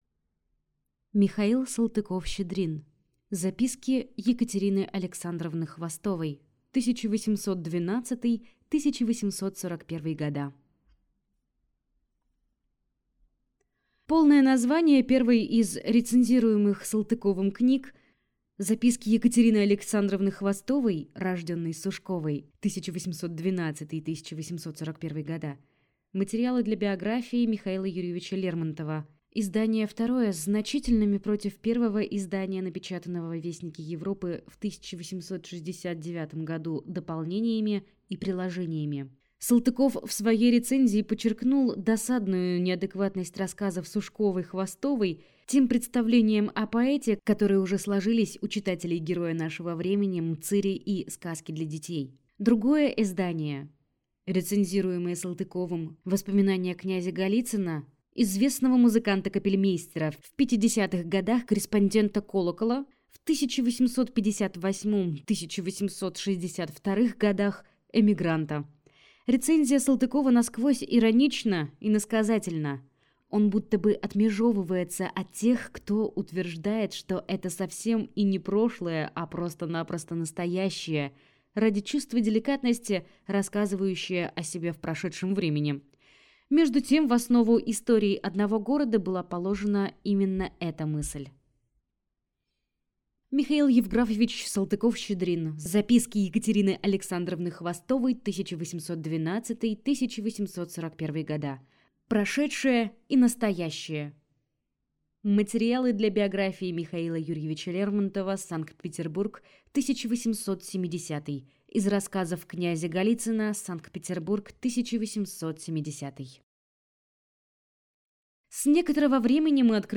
Аудиокнига Записки Е. А. Хвостовой. 1812–1841…